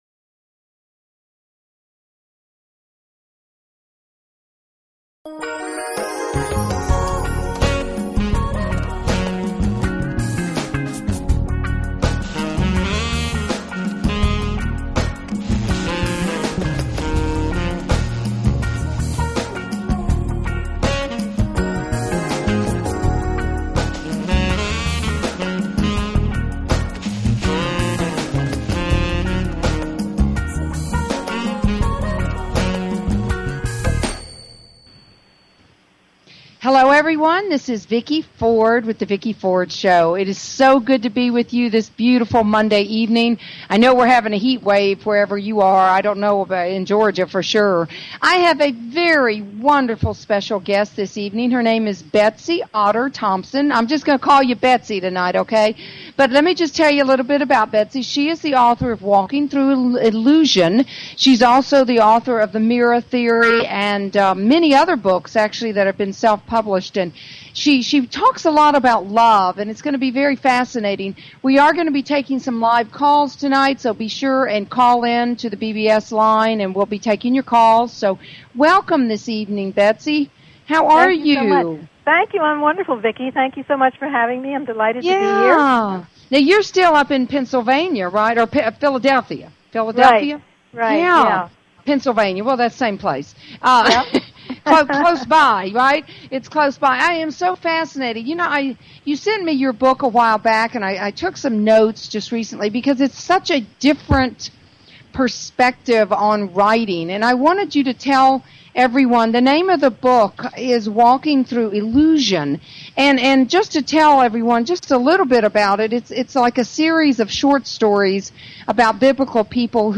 Talk Show Episode, Audio Podcast
Talk Show